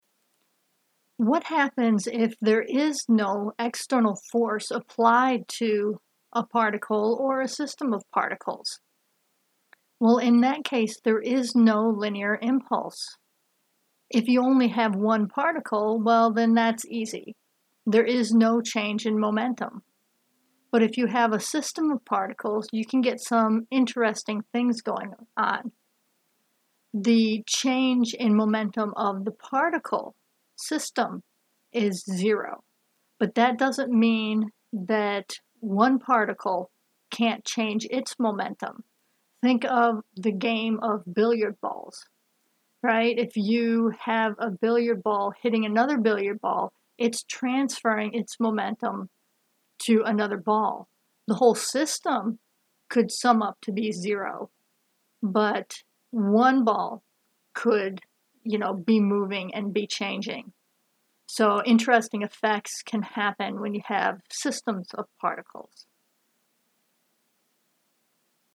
Lecture content